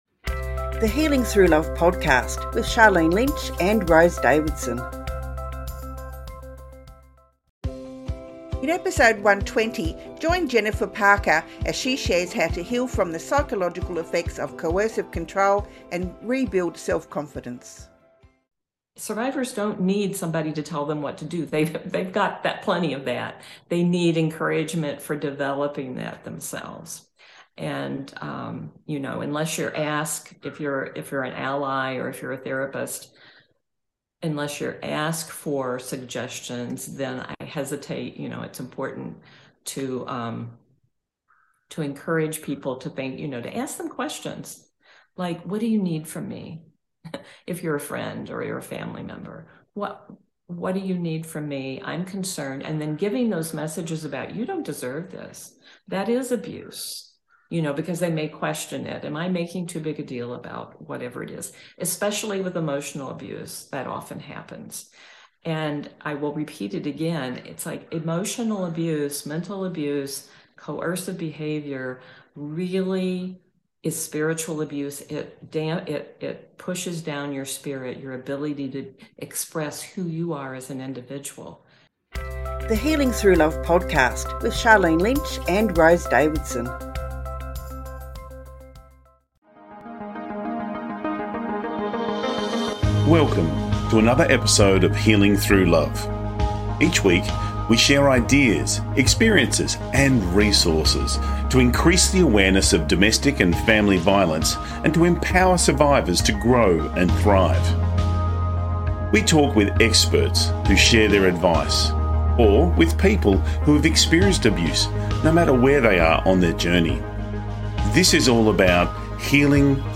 a seasoned therapist and expert on intimate partner abuse